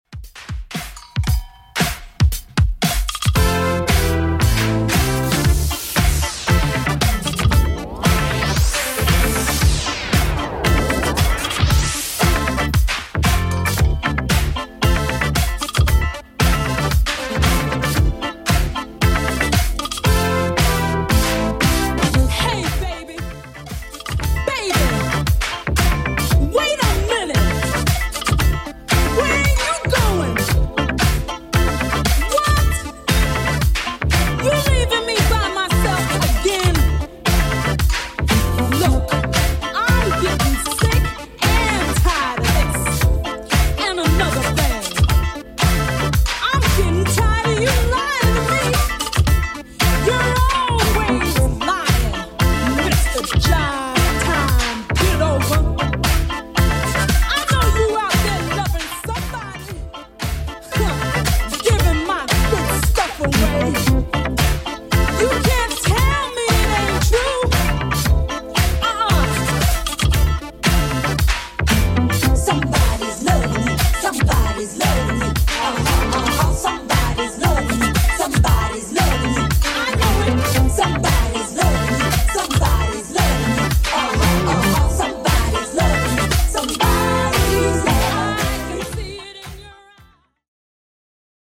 Genre: 80's
BPM: 125